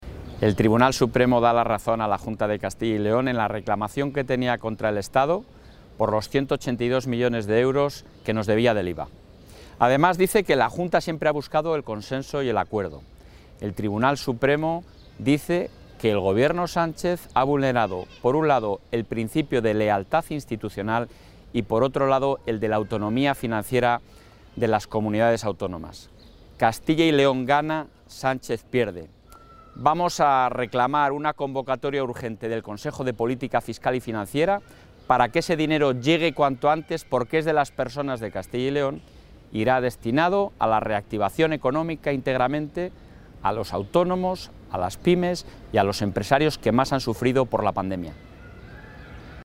El presidente de la Junta de Castilla y León, Alfonso Fernández Mañueco, valora la sentencia de 19 de abril de 2021 del Tribunal Supremo que estima el recurso contencioso administrativo presentado por el Ejecutivo autonómico en relación a los 182 millones de euros del IVA no abonado en la liquidación de del Sistema de Financiación autonómica de 2017.